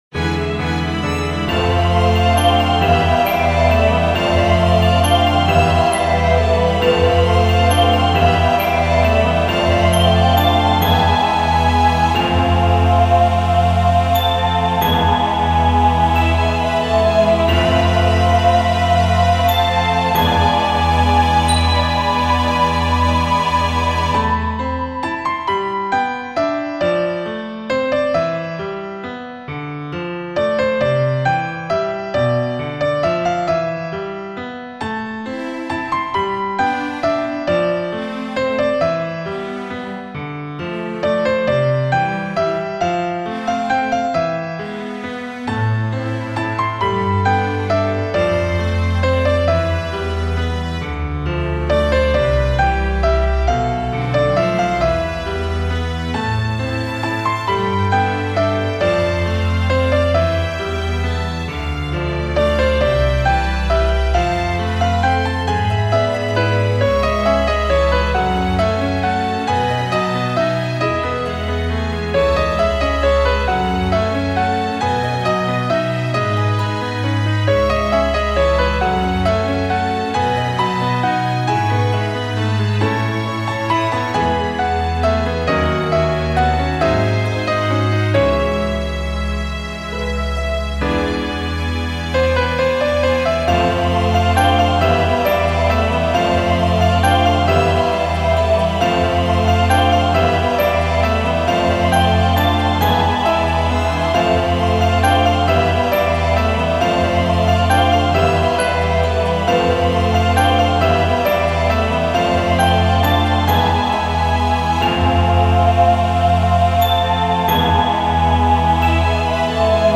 フリーBGM イベントシーン 感動的・ドラマチック
フェードアウト版のmp3を、こちらのページにて無料で配布しています。